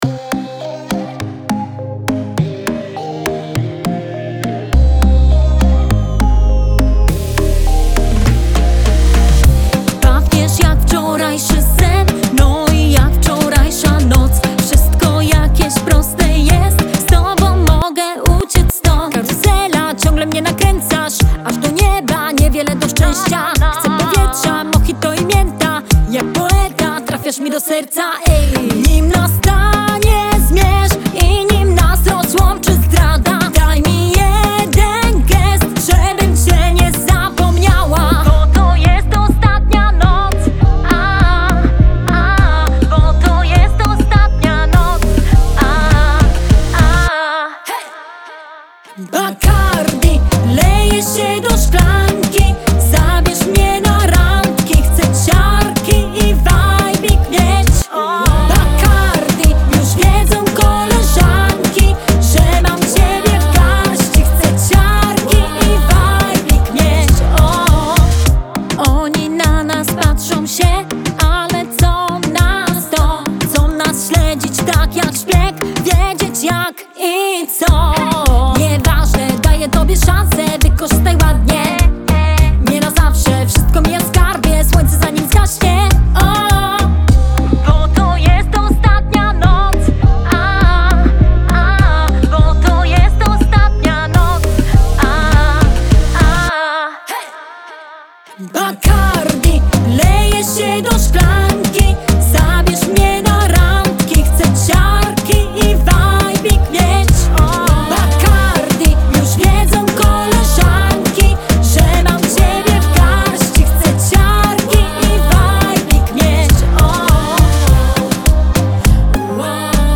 disco-dance, latino